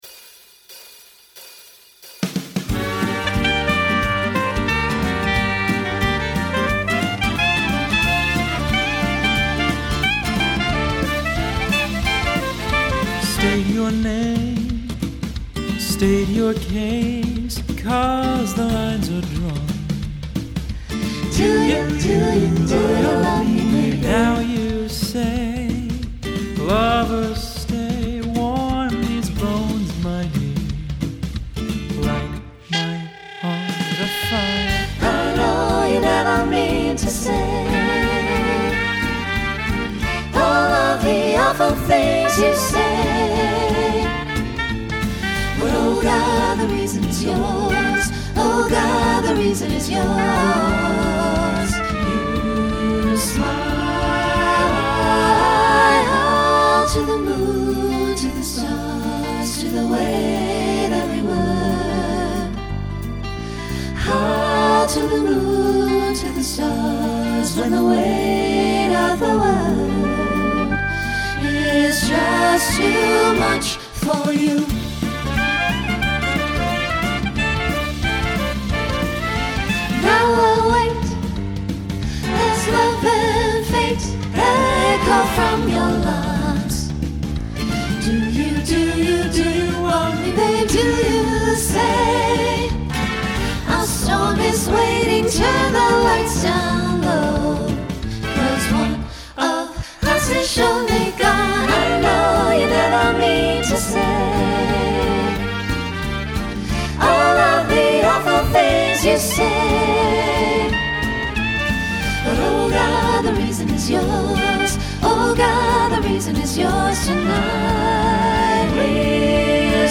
Swing/Jazz Instrumental combo
Mid-tempo , Solo Feature Voicing SATB